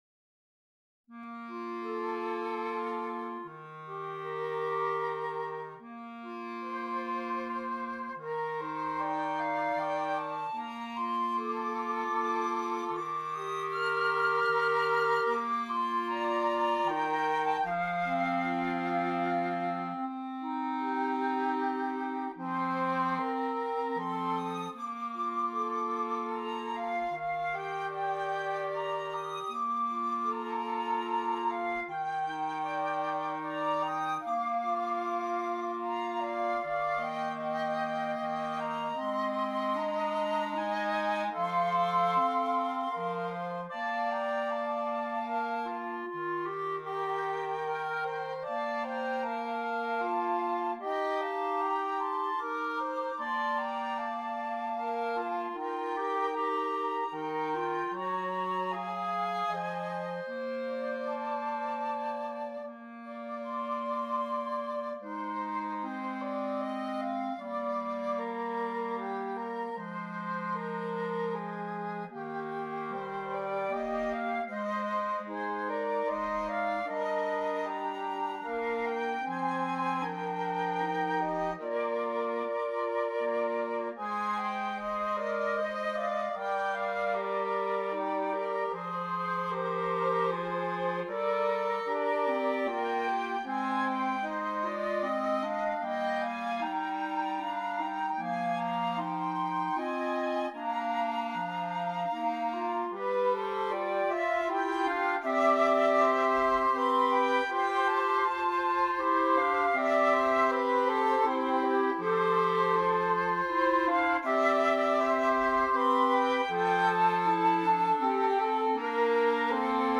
2 Flutes, 2 Clarinets